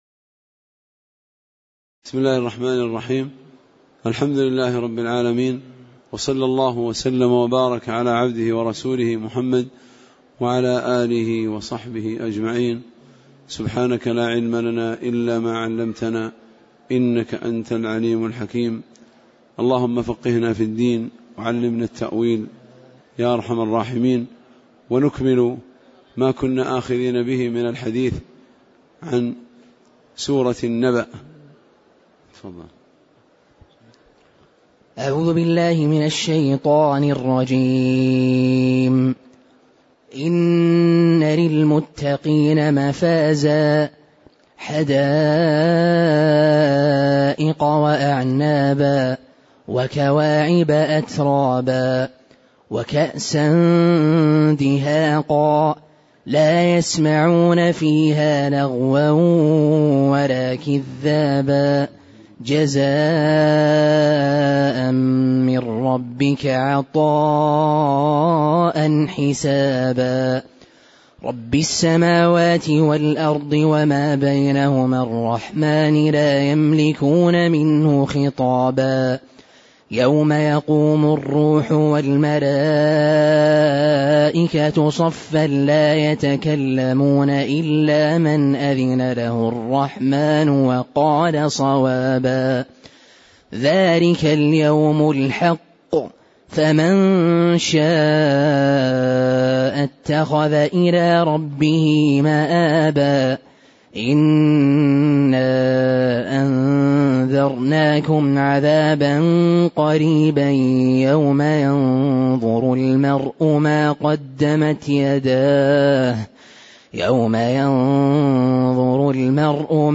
تاريخ النشر ١٢ شوال ١٤٣٨ هـ المكان: المسجد النبوي الشيخ